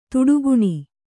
♪ tuḍuguṇi